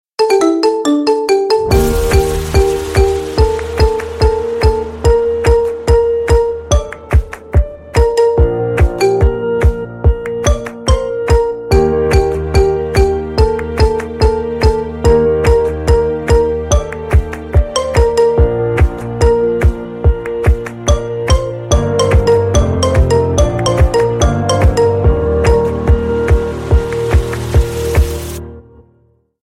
Рингтоны Без Слов
Рингтоны Ремиксы